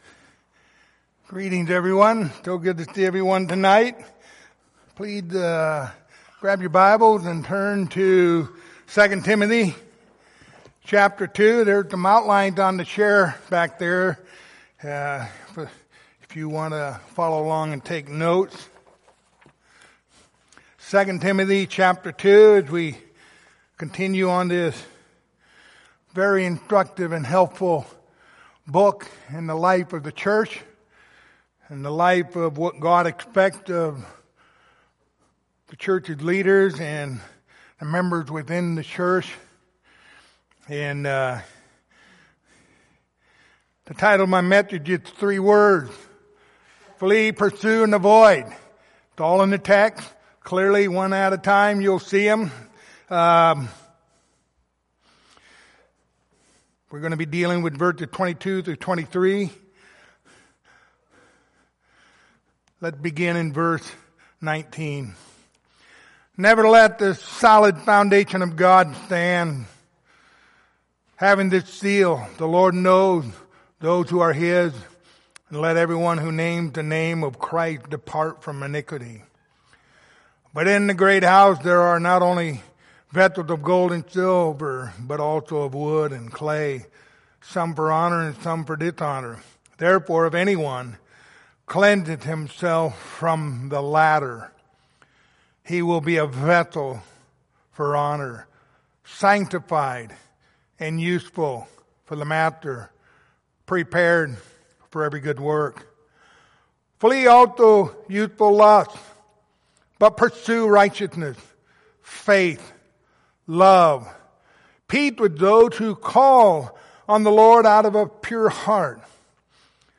Pastoral Epistles Passage: 2 Timothy 2:22-23 Service Type: Sunday Evening Topics